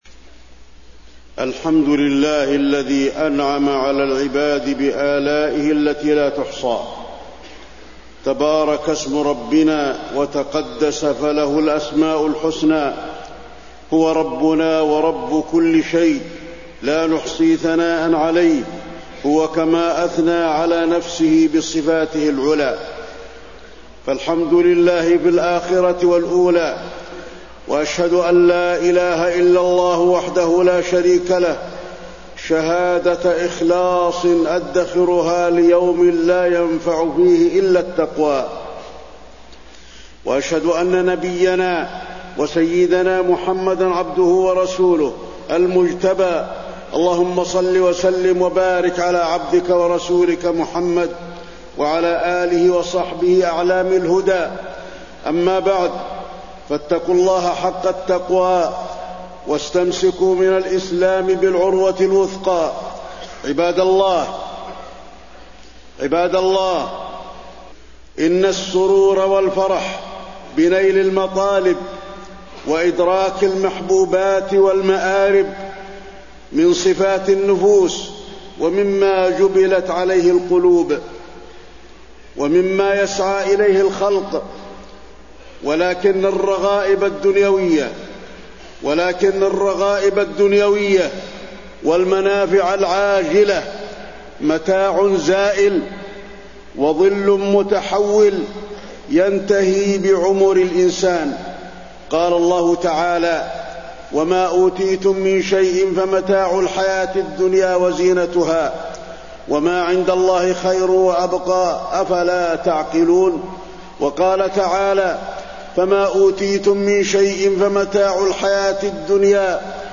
تاريخ النشر ١٣ ذو الحجة ١٤٣١ هـ المكان: المسجد النبوي الشيخ: فضيلة الشيخ د. علي بن عبدالرحمن الحذيفي فضيلة الشيخ د. علي بن عبدالرحمن الحذيفي ما عند الله خير وأبقى